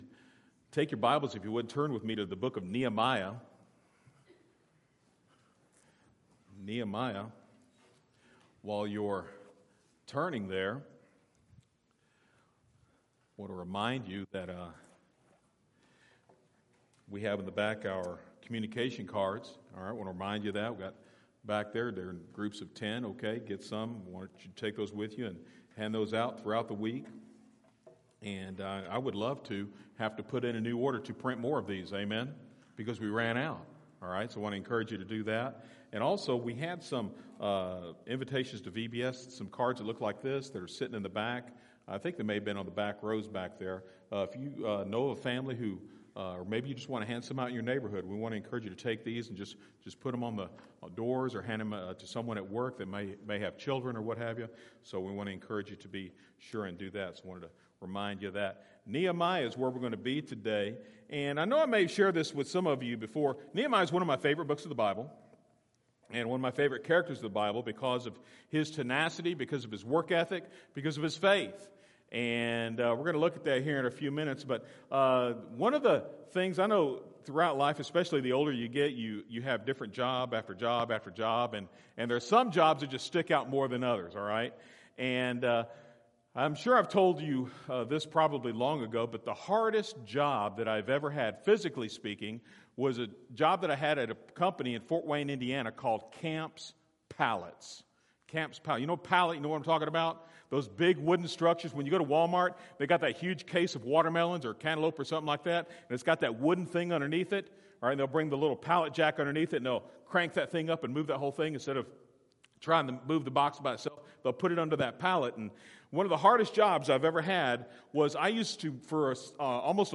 A message from the series